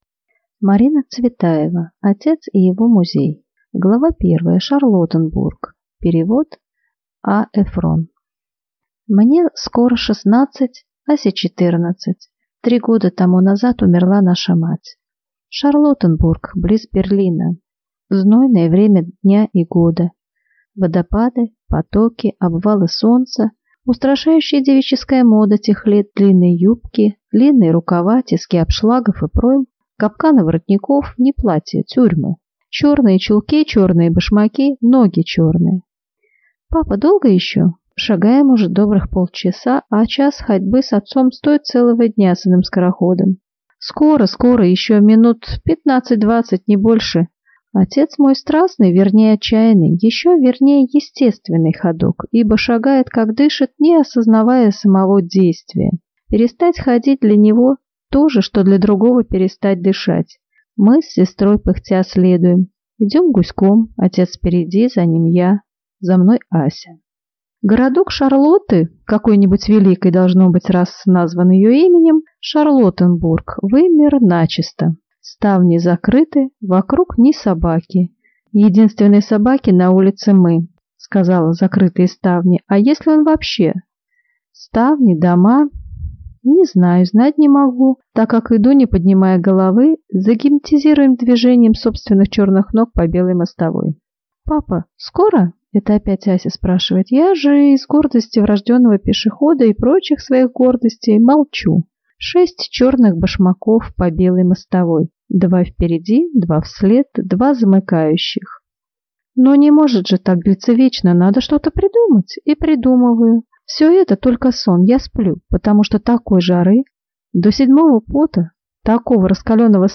Аудиокнига Отец и его музей | Библиотека аудиокниг